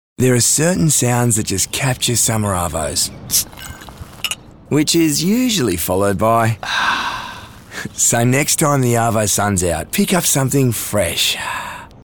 He manages to make the most retail of retail reads sound cool and can tug at the heartstrings when required.